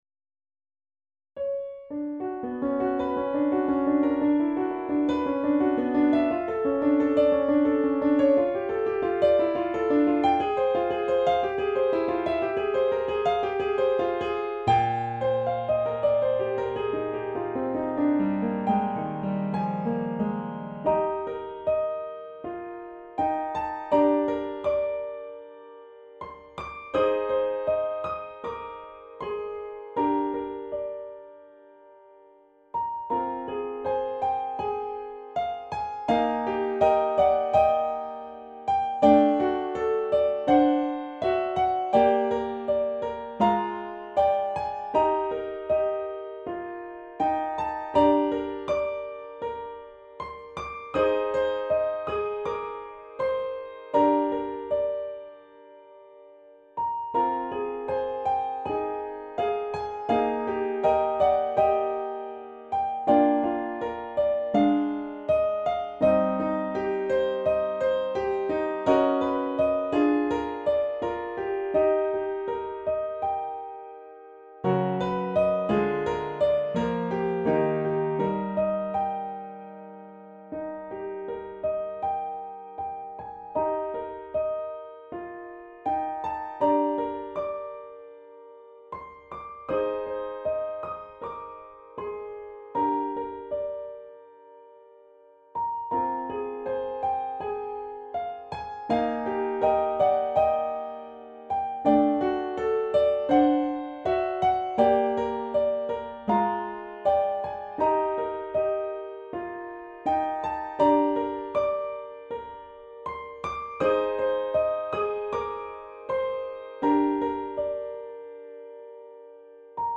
弾いてみた